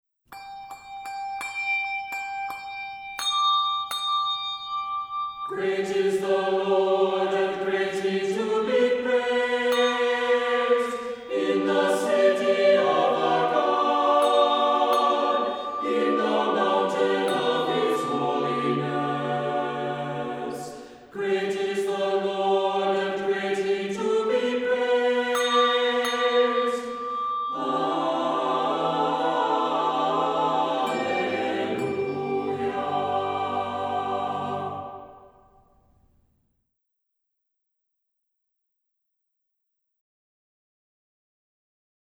Voicing: SATB with Bells